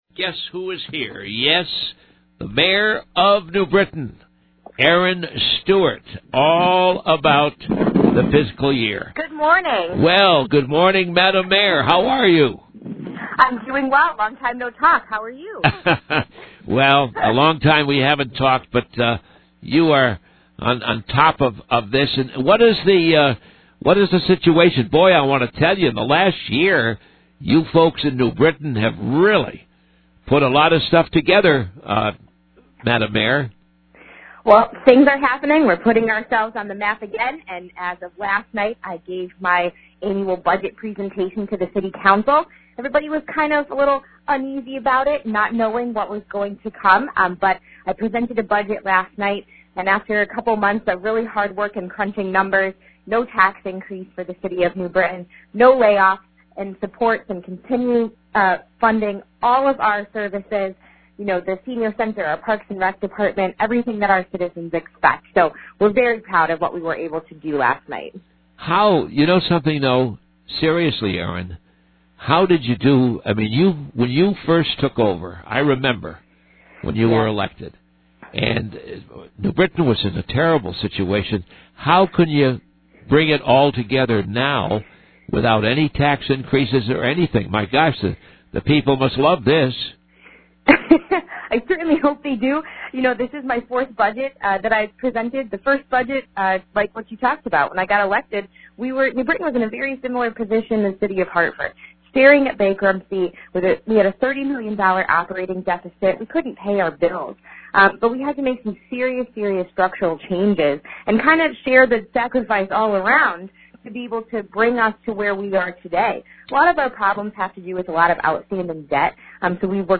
New Britain Mayor Erin Stewart has announced her budget, and she is holding the line on taxes. She talks about her plan in this interview.